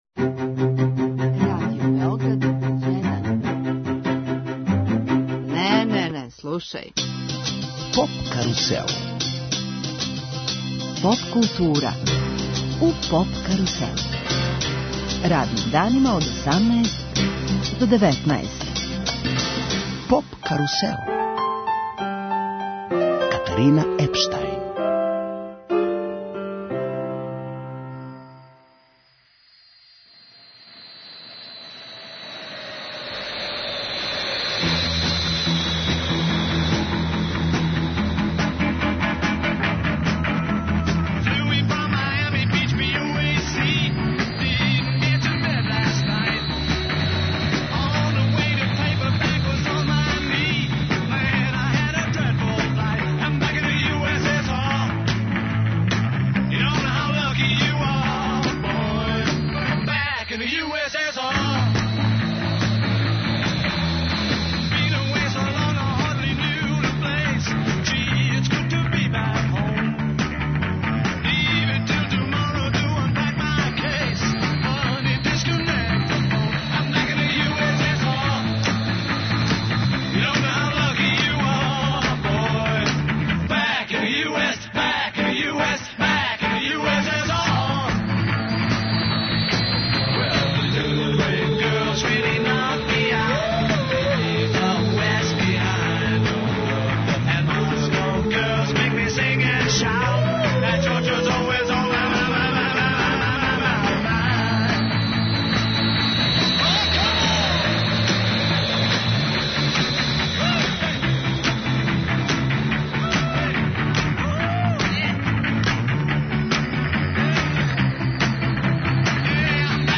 Поводом 50 година од објављивања Белог албума Битлса, формирала се музичка група Oceanchild, која ће овај јубилеј да обележи серијом концерата на којима ће да изведе свих 30 нумера. Гости емисије су чланови овог састава.